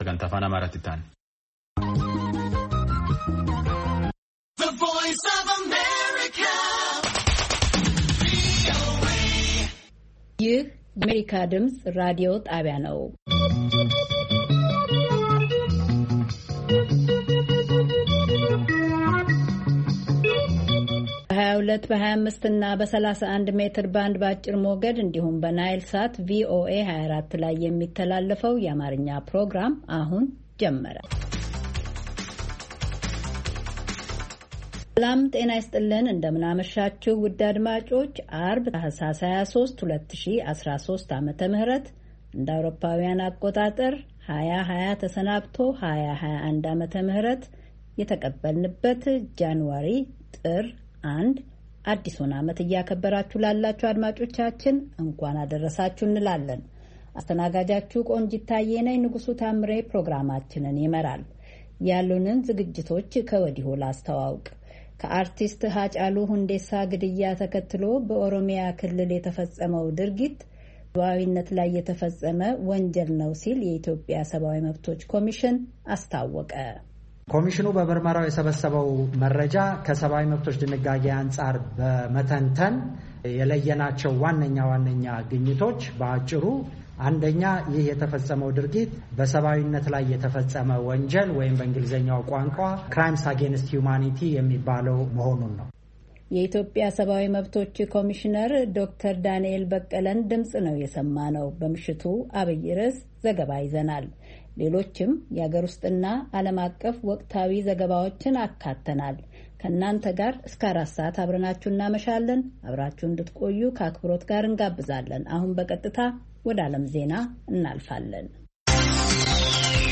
ዐርብ፡-ከምሽቱ ሦስት ሰዓት የአማርኛ ዜና